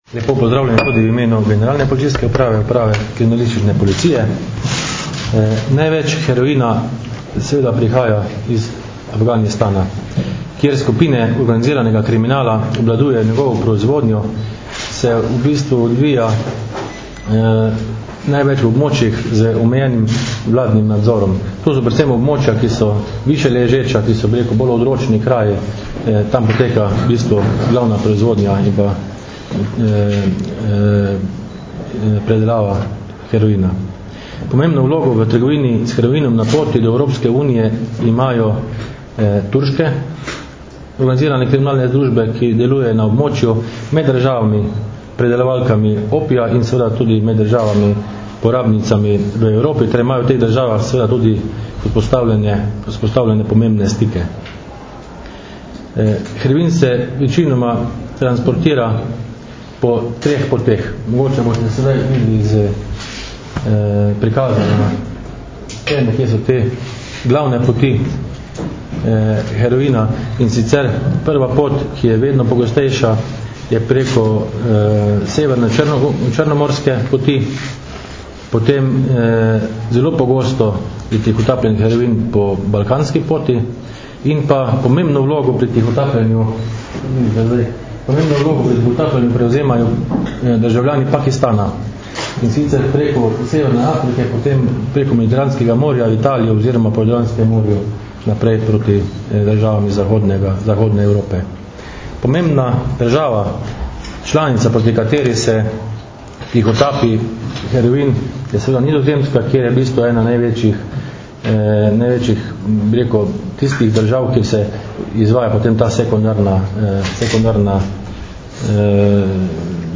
Informacija z novinarske konference: Na današnji novinarski konferenci na Carinskem uradu v Mariboru so predstavniki Carinske uprave RS in Generalne policijske uprave podrobneje predstavili nedavno odkritje večje količine prepovedane droge.